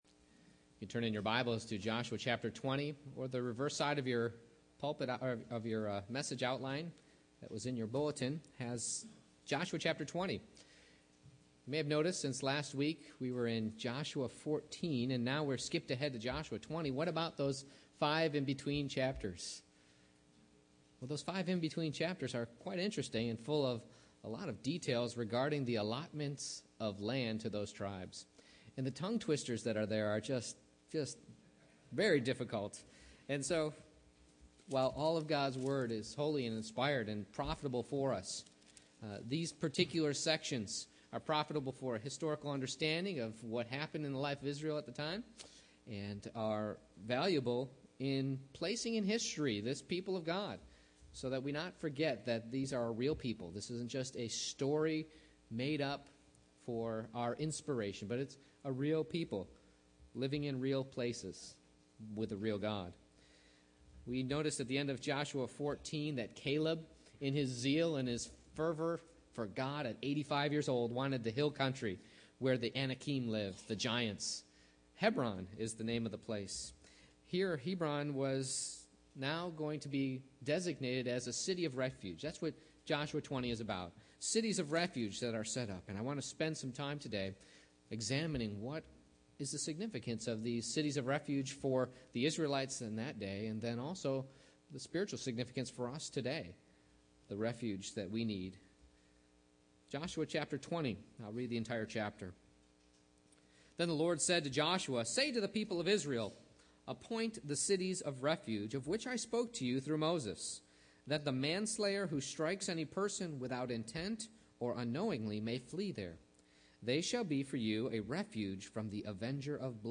Joshua 20:1-9 Service Type: Morning Worship I. What Is A City of Refuge?